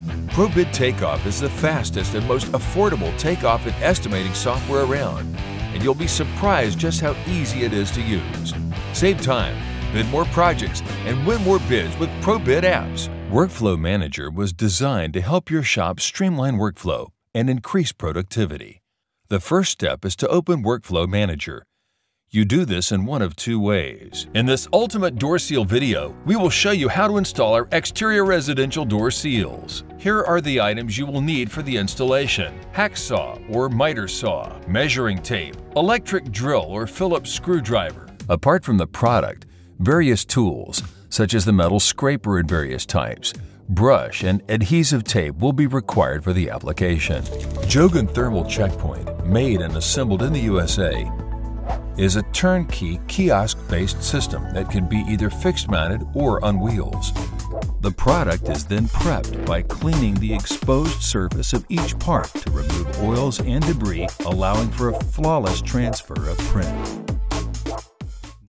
Powerful, deep male Voiceover with over 27 years experience in all kinds of projects
E Learning
Texan Southwestern
E Learning.mp3